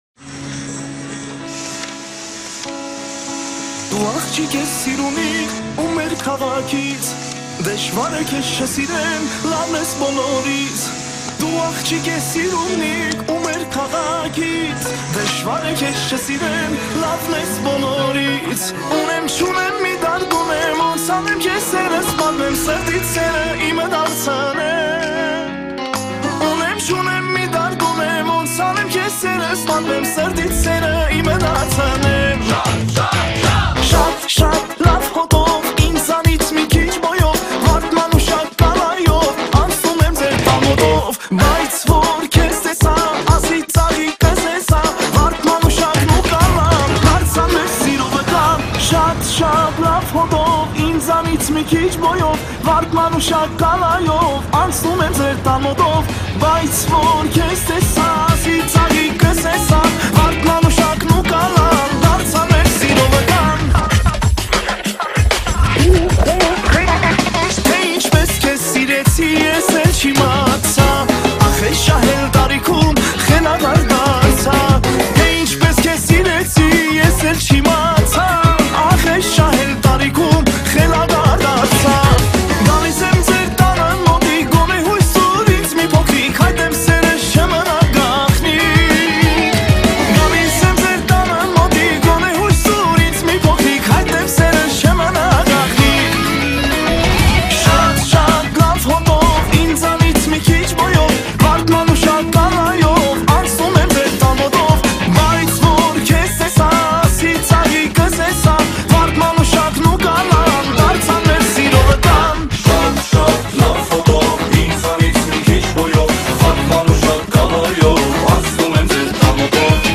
հետաքրքիր կատարում, թույն ռաբիզ